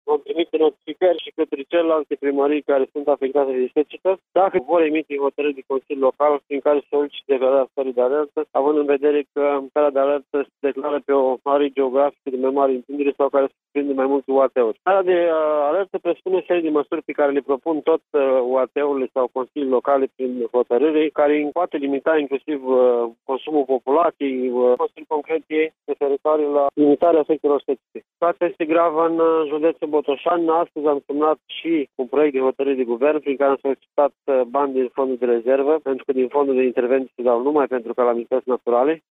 Prefectul de Botoșani, Dan Nechifor, a declarat că situația este mult mai gravă decât pare: